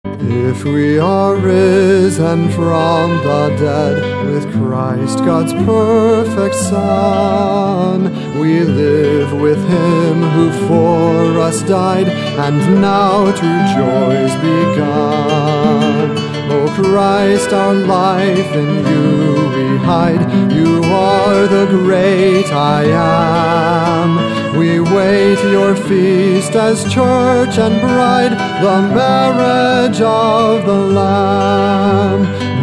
Songs for Voice & Guitar